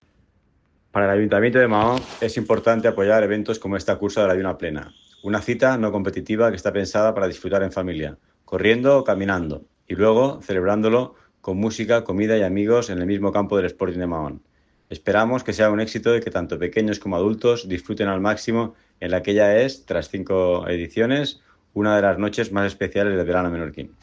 Declaraciones del regidor de Deportes del Ayuntamiento de Maó, Lázaro Alcaide: